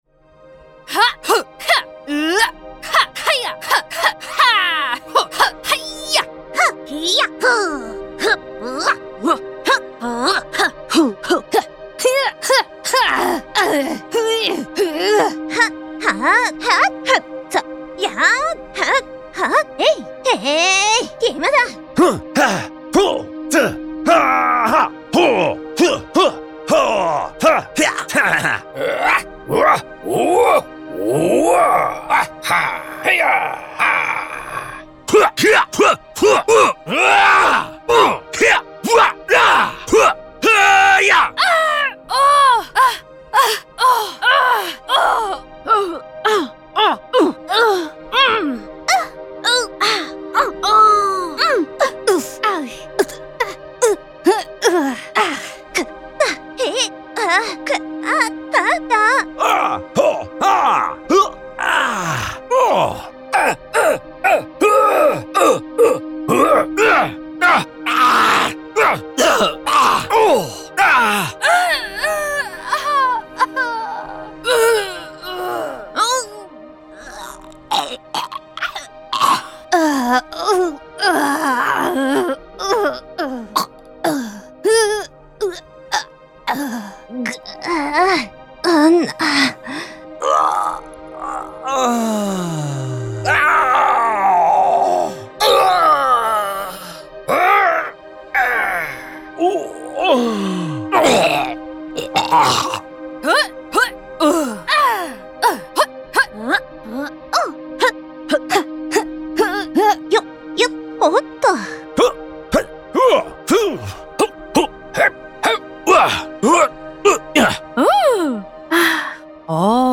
动漫角色动作音效素材库 Epic Stock Media Anime Character Vocalizations
Anime Character Vocalizations(动漫角色配音)是一套专门为动漫作品、游戏、影视项目设计的动漫角色发声音效素材库。它包含了1102个由7位配音演员录制的动漫角色发声音效，包括不同角色的哭泣、死亡、战斗、待机、跳跃、大笑和受伤等场景音效。
声道数：立体声